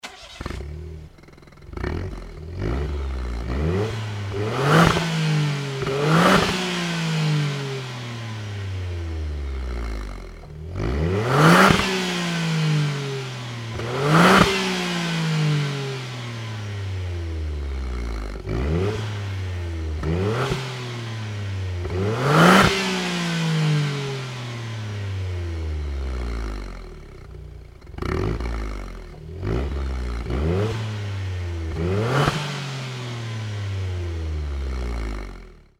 GTbox06&S専用サイレンサー（空吹かし）
copen_la400-kakimoto_genuine_baffle_acceleration.mp3